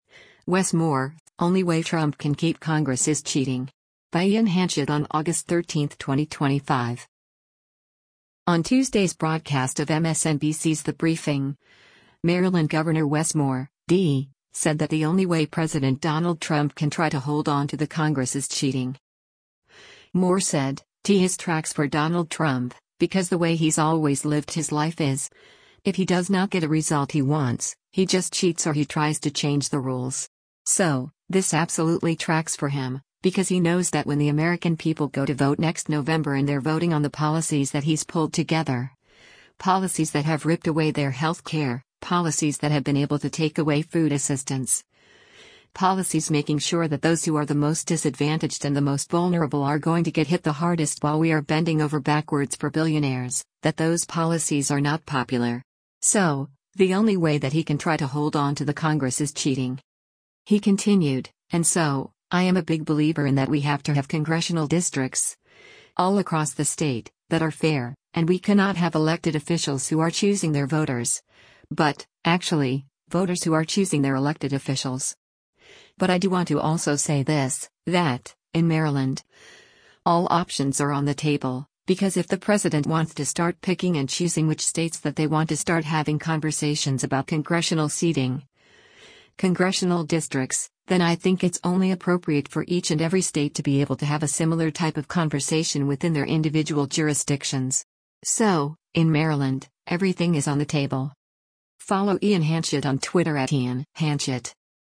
On Tuesday’s broadcast of MSNBC’s “The Briefing,” Maryland Gov. Wes Moore (D) said that “the only way” President Donald Trump “can try to hold on to the Congress is cheating.”